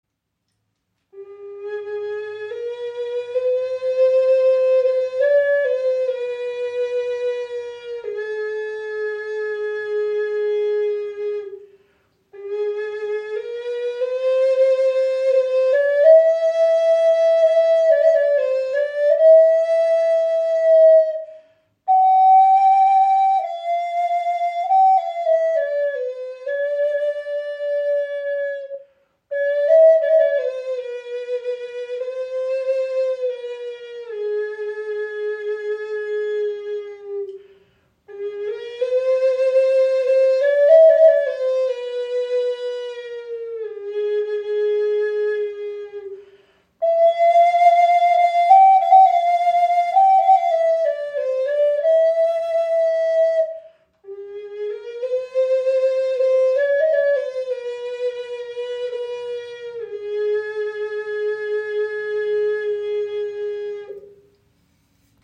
Okarina aus einem Aststück | A4 in 432 Hz | Aeolian Stimmung | ca. 20 cm
Handgefertigte 6 Loch Okarina aus Teakast – klarer, warmer Klang in Aeolischer Stimmung in A (432 Hz), jedes Stück ein Unikat.
Die Okarina spricht besonders klar an und erklingt in der Aeolischen Stimmung in A4, fein abgestimmt auf 432 Hz – ein Ton, der Herz und Geist in harmonische Schwingung versetzt.
Trotz ihrer handlichen Grösse erzeugt sie einen angenehm tiefen, warmen Klang – fast ebenbürtig zur nordamerikanischen Gebetsflöte.